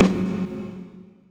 Snares
REVVVE_SNR.wav